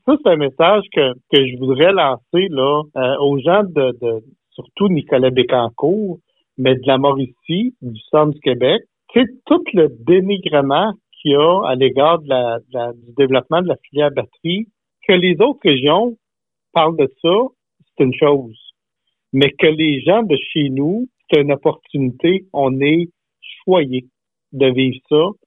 Sur les ondes de VIA 90.5 FM, le député de Bécancour-Nicolet a mentionné qu’il souhaiterait que la population soit plus optimiste à l’égard de ce projet.